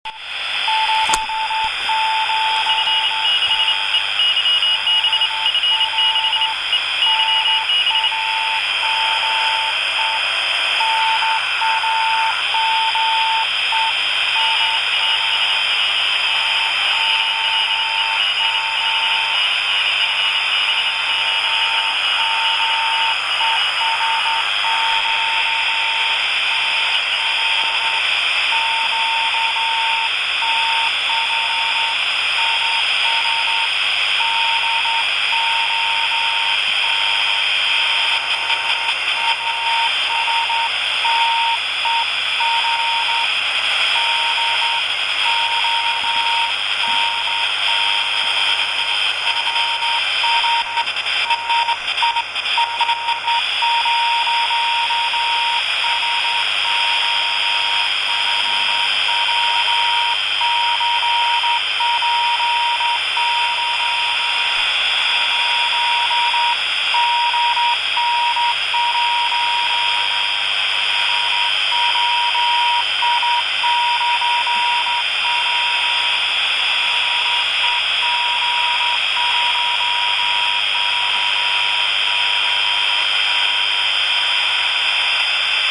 Также, если кому интересно, аудио- запись приёма на приёмнике прямого преобразования, с конвертером, 14 мГц.